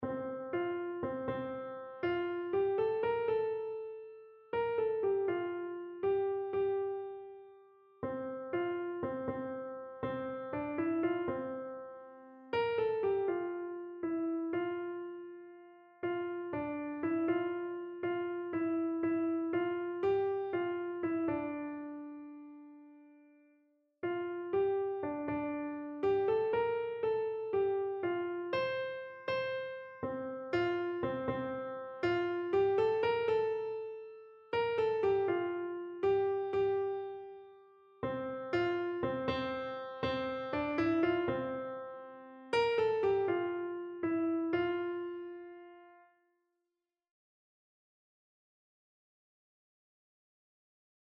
Kinderlied Wort Gottes
Notensatz 1 (Unisono)